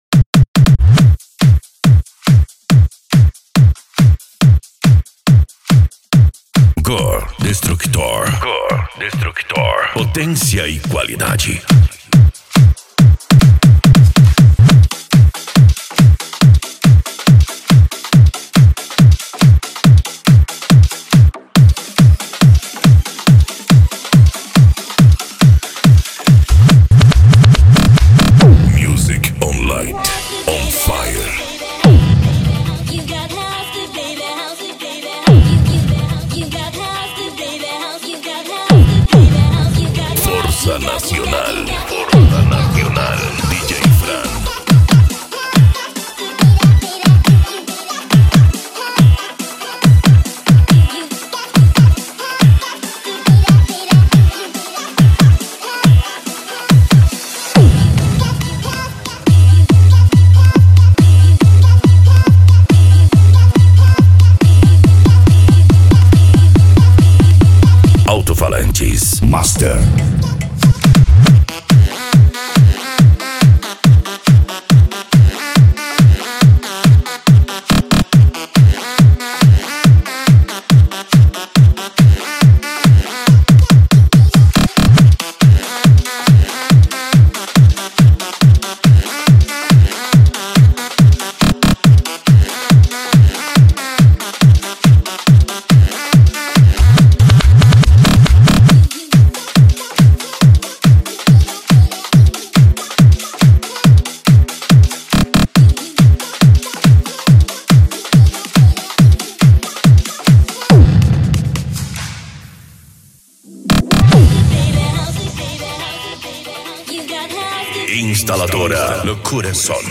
Bass
Funk
Remix